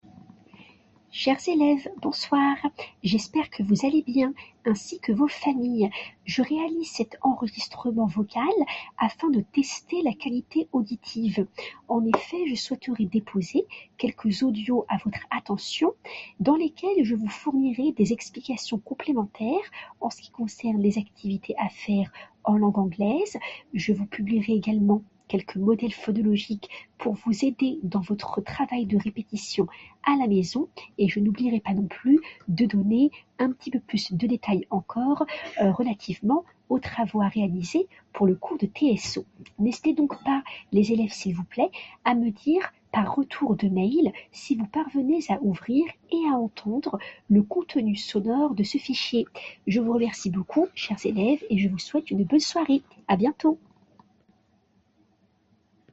TEST - ENREGISTREMENT VOCAL DU PROFESSEUR